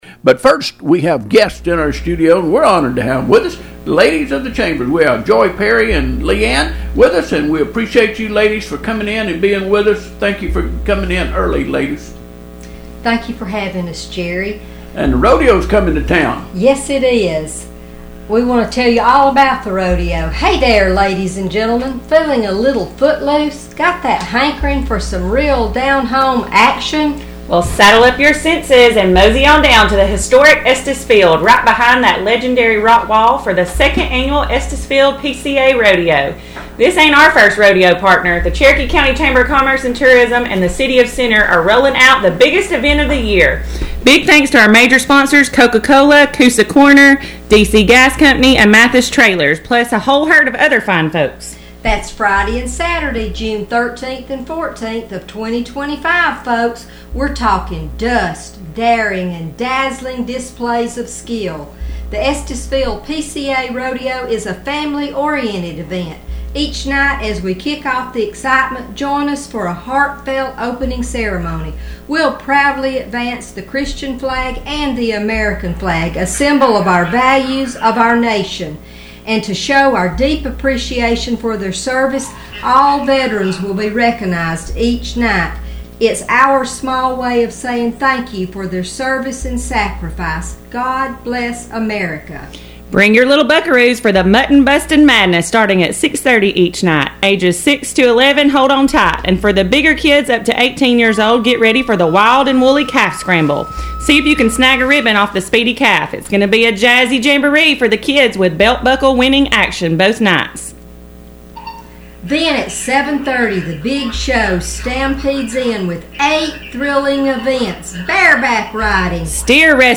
Rodeo-Interview-with-Chamber-61125.mp3